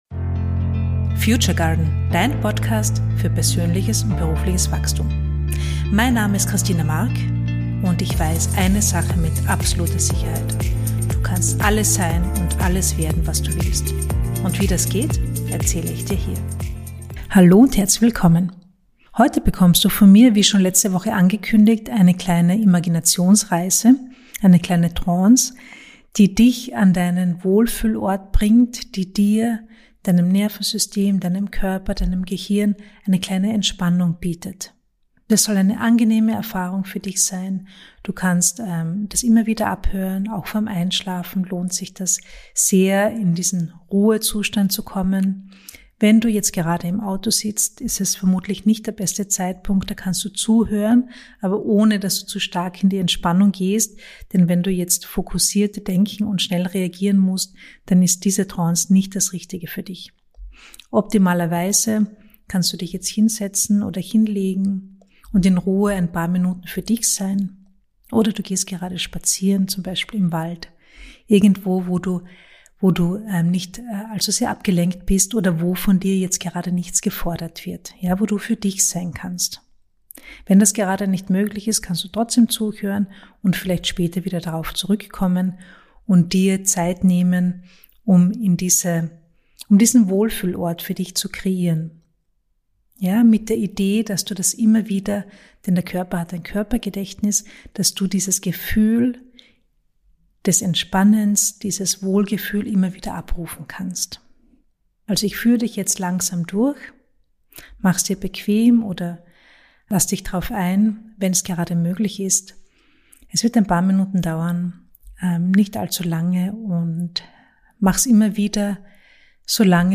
In dieser Folge bekommst du eine geführte Hypnose, die dich in die Entspannung bringt.